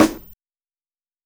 CG_Snr (66).WAV